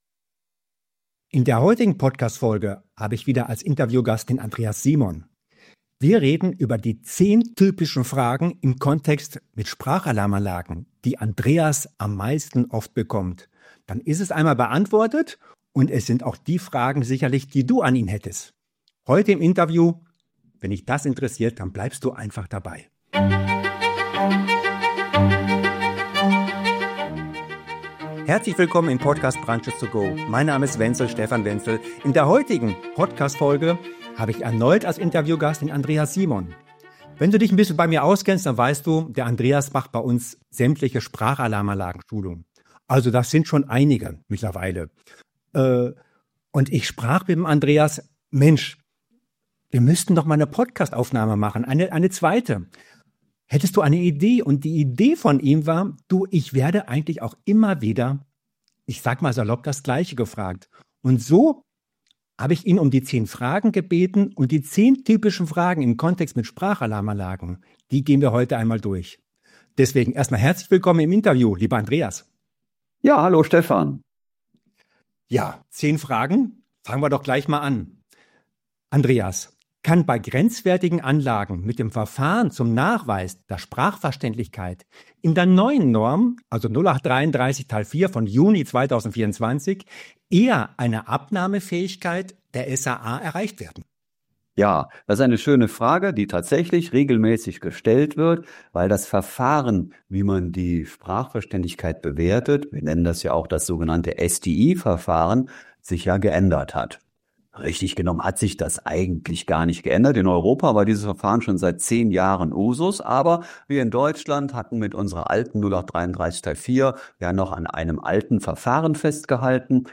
#173 Interview